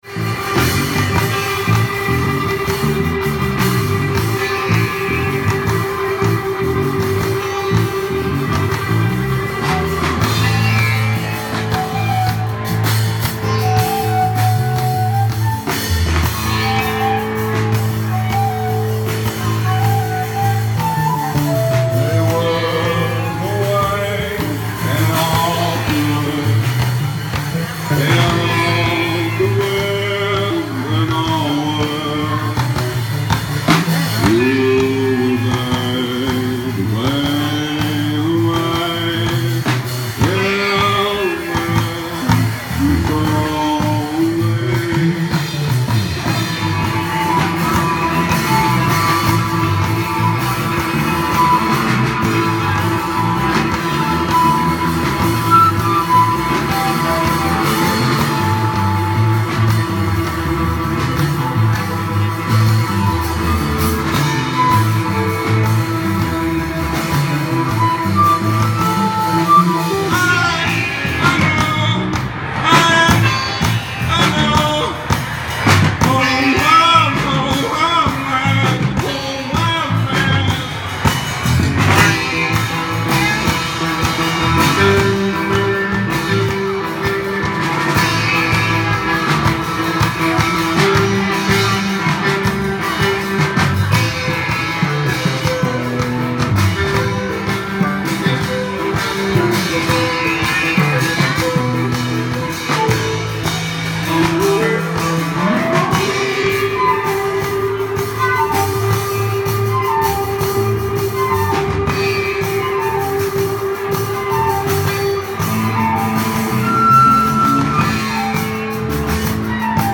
ALL MUSIC IS IMPROVISED ON SITE
In Arms The Word voice/guitar
flute
drums
sitar